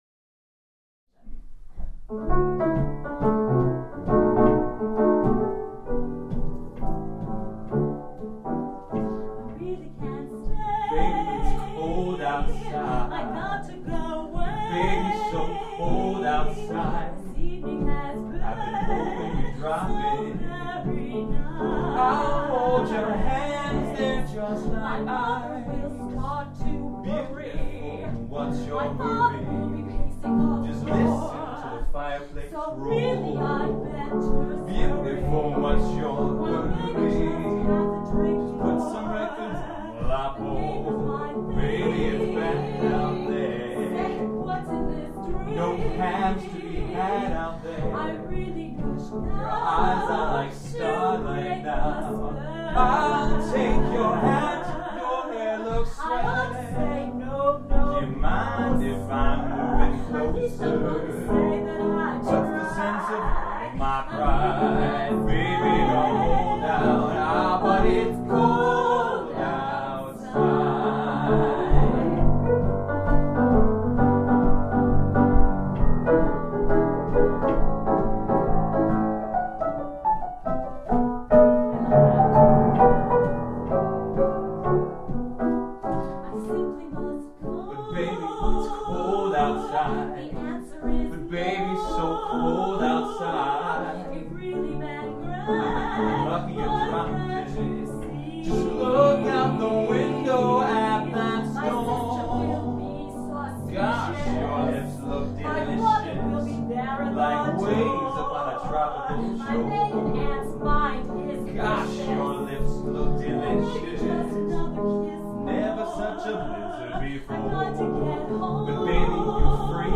Jazz Audio samples: